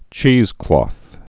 (chēzklôth, -klŏth)